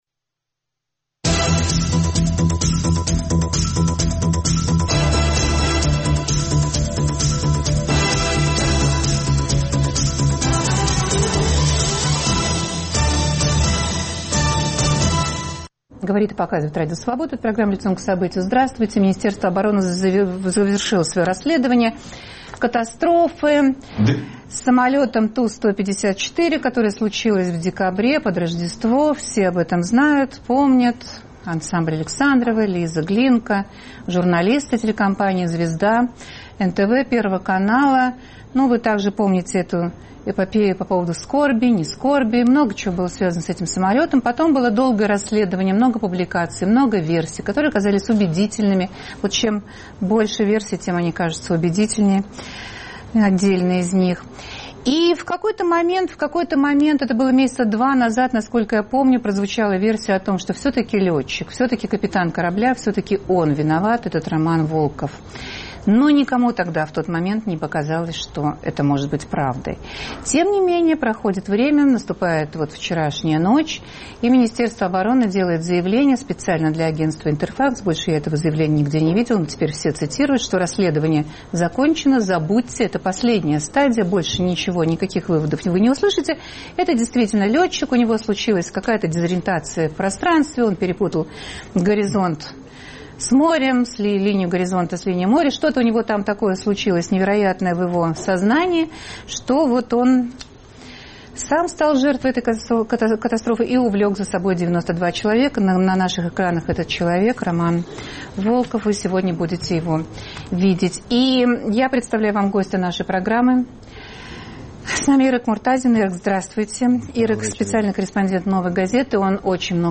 Согласны ли с этим выводом журналисты, которые писали о катастрофе, и специалисты? В студии